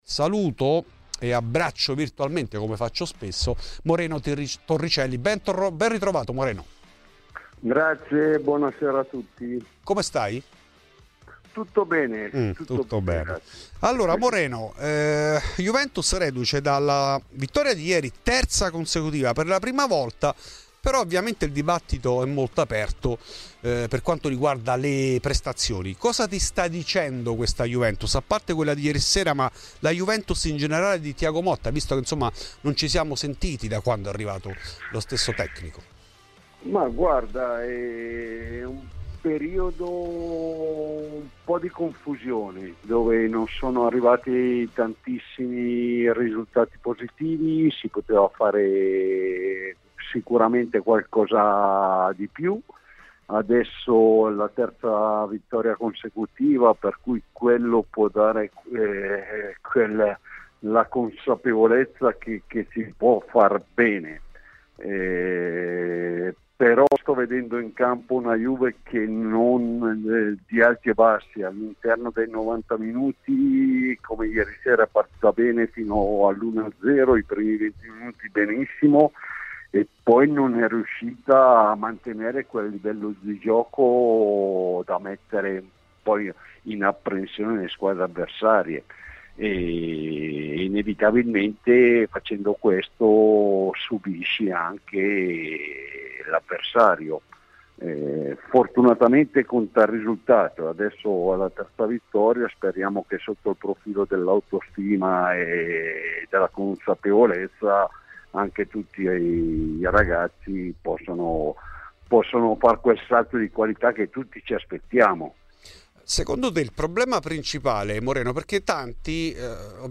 In ESCLUSIVA a Fuori di Juve Moreno Torricelli non risparmia critiche a tecnico, con una frecciatina anche alla società.